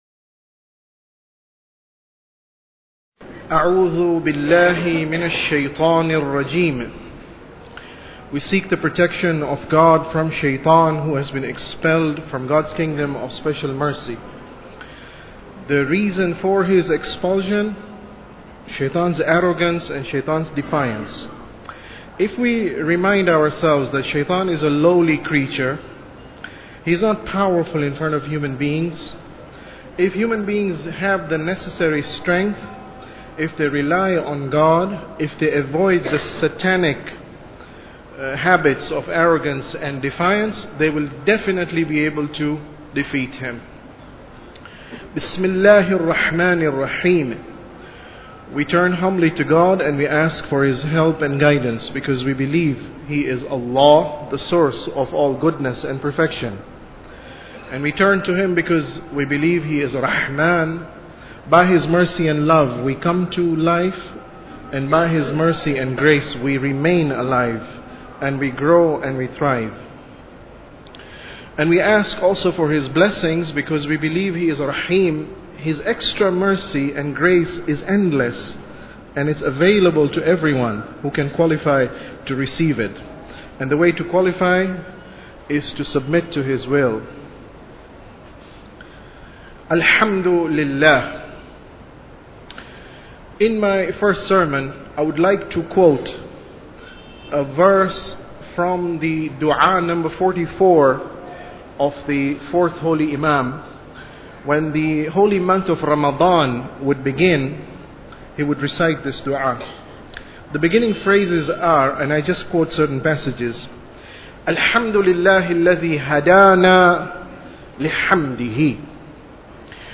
Sermon About Tawheed 16